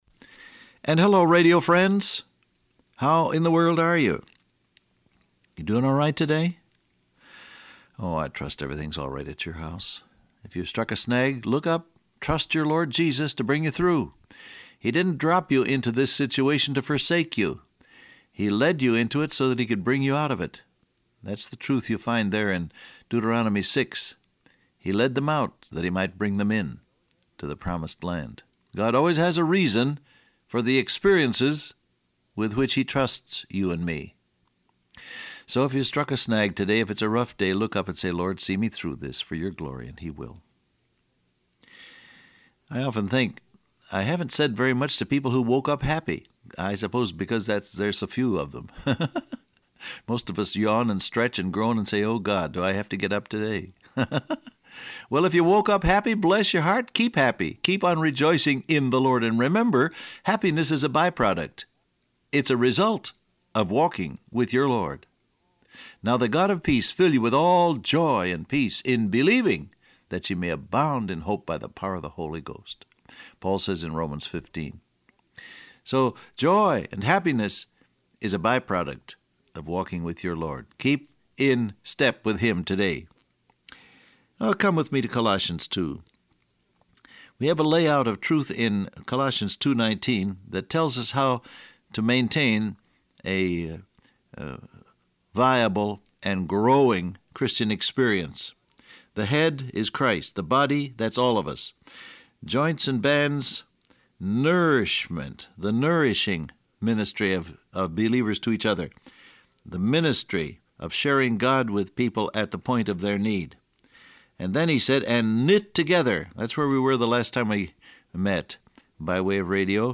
Download Audio Print Broadcast #1897 Scripture: Colossians 2:19 , Deuteronomy 6, Acts 5:13, Timothy 2:2 Transcript Facebook Twitter WhatsApp And hello, radio friends, how in the world are you?